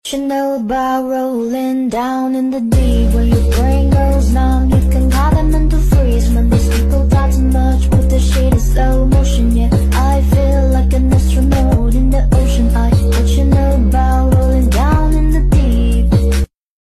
Akamaru: Jugando a las mordidas sound effects free download